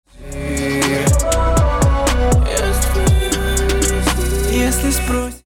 На тему креативного дилея еще интересуют вот этот эффект на голосе в файле... Звучит как такие стрекотания что ли, похоже на 1/32, толи гранулярные какие то вещи.